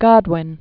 (gŏdwĭn), William 1756-1836.